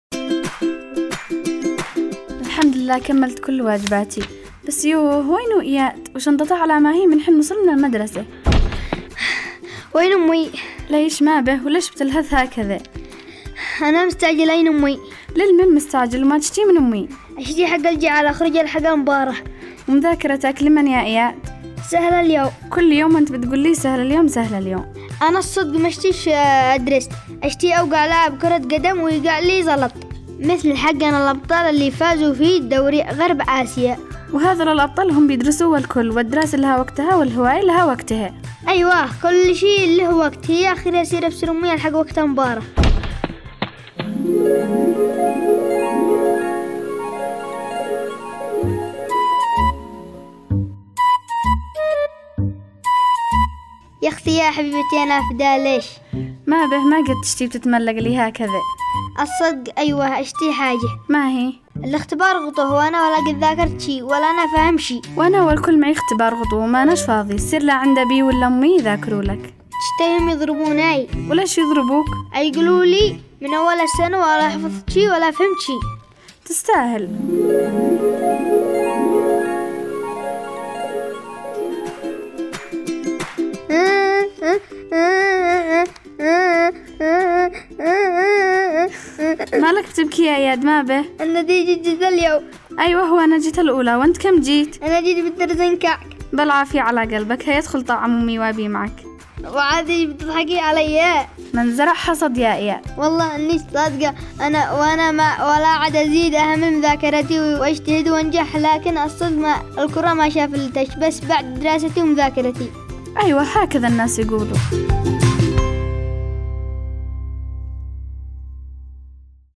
برنامج انا واياد  تستمعون إليه عبر إذاعة صغارنا كل احد الساعة 1:00 ظهرا
حلقات دراميه تناقش مواضيع تخص الطفل والوالدين